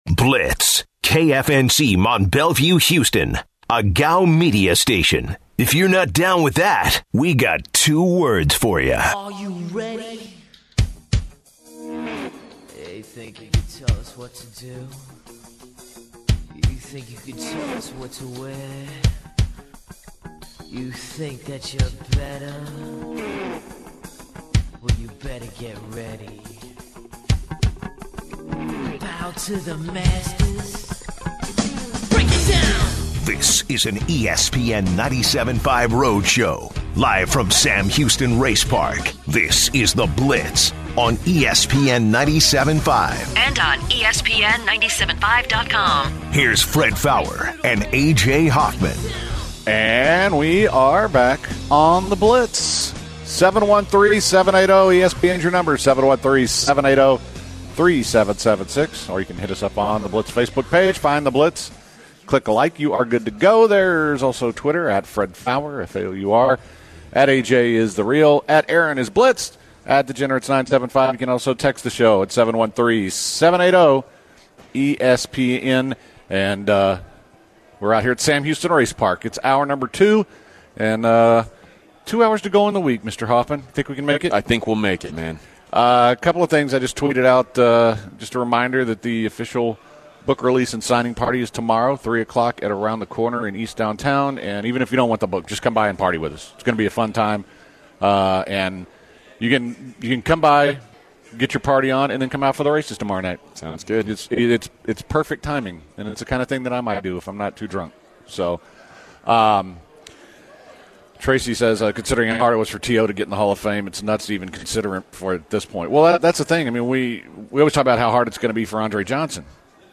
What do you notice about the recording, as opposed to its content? The guys are at Sam Houston Race Park and continue their conversation about Julian Edelman’s credentials. Then, they look at the fight between Ian Cole and Tom Wilson. They step away from sports for a second to discuss the TV shows they watch. They turn back to sports and talk the MLB.